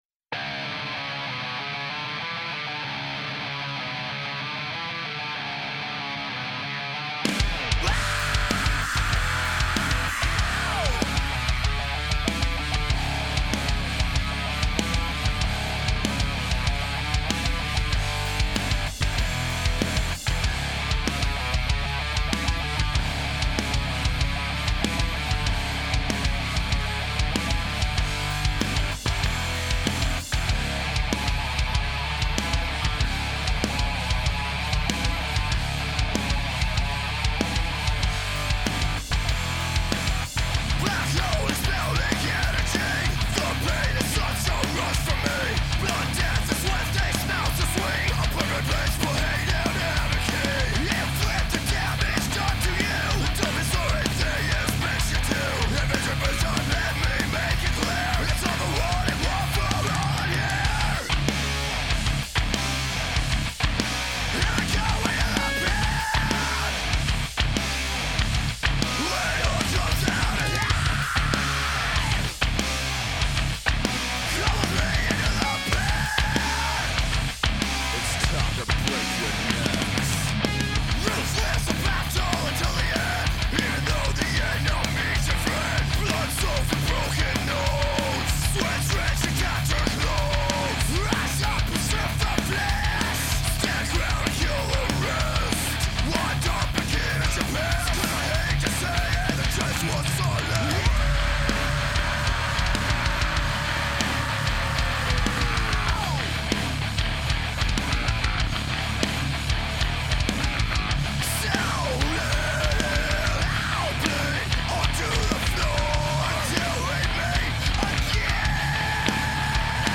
heavy metallers
demo